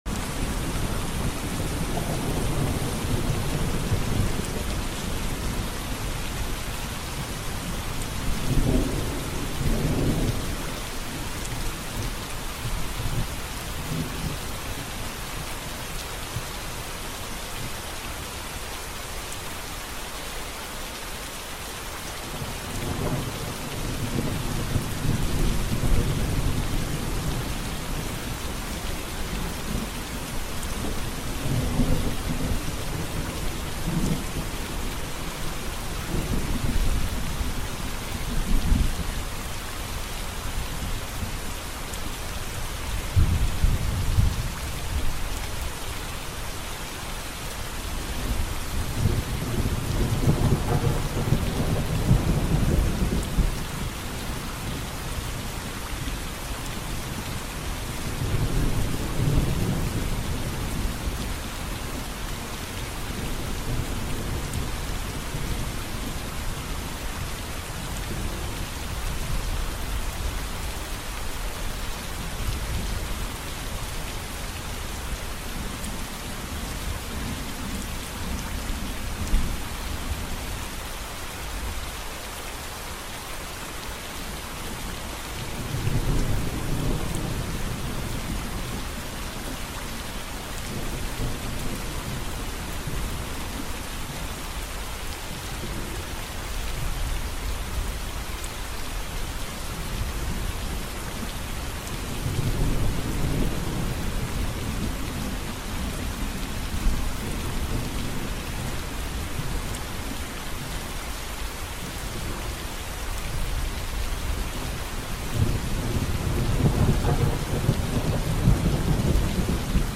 Escape into the soothing rhythm of rain falling softly against a quiet home in the woods. The calming sound embraces your thoughts, slows your breathing, and guides you gently into deep, restorative sleep. Perfect for relaxation, meditation, or a nightly sleep ritual.